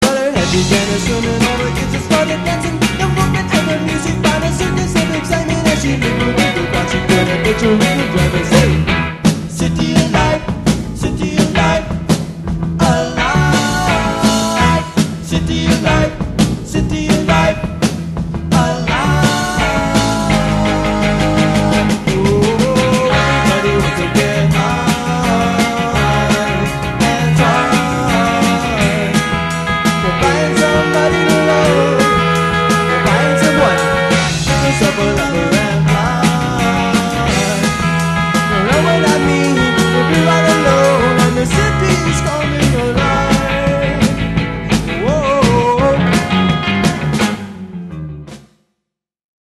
voc/gtr
voc/bass
drums.